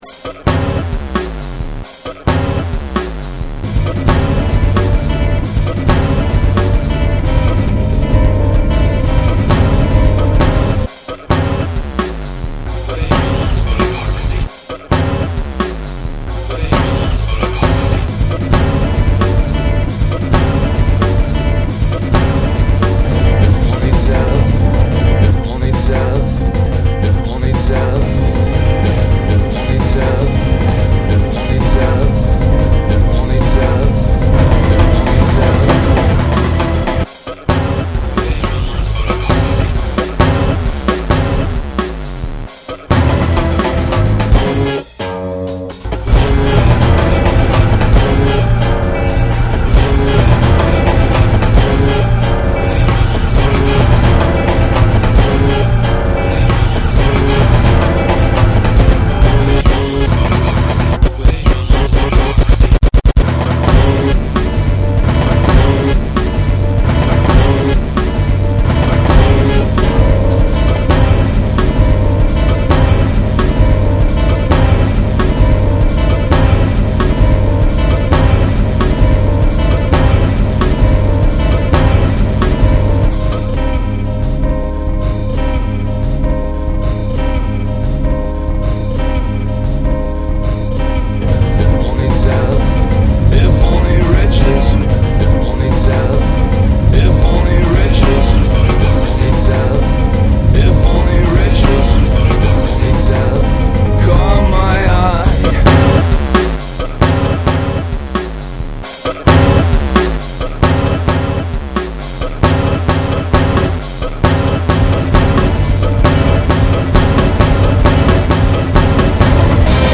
bone-crushing
remix